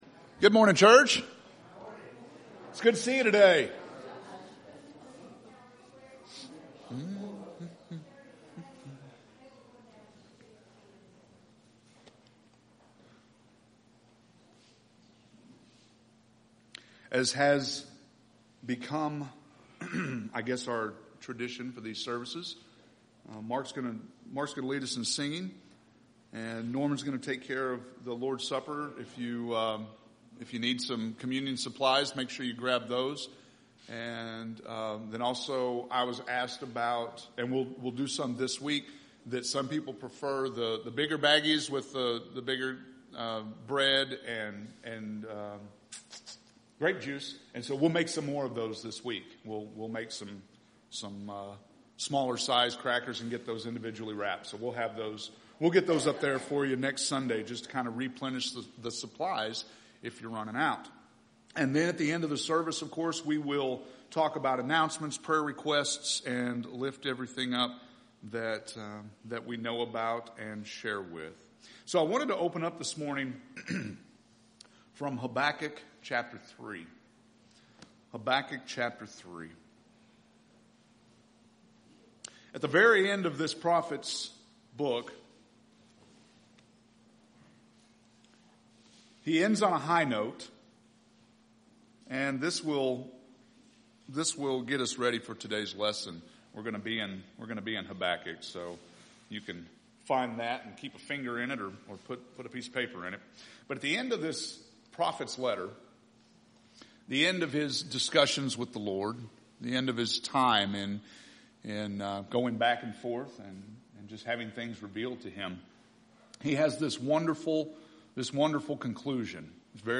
July 26th – Sermons